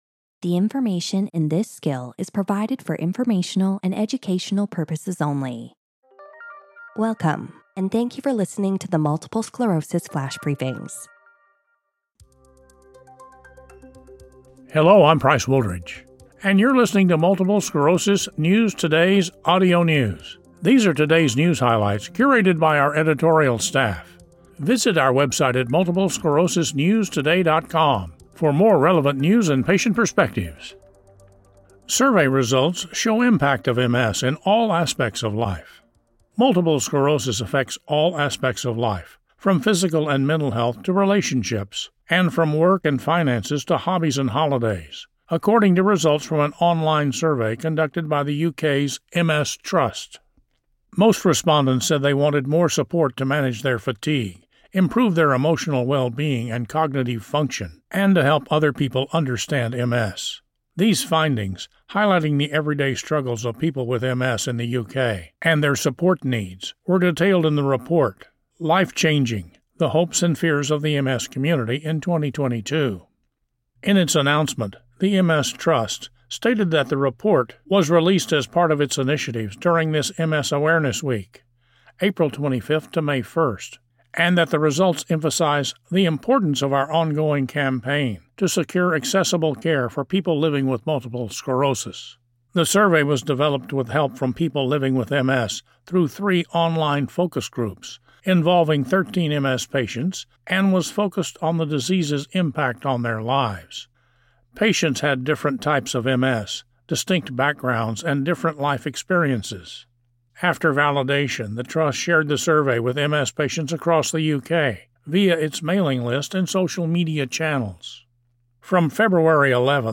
reads an article about how MS affects all aspects of life, from physical and mental health to relationships and hobbies, according to results from a U.K. online survey.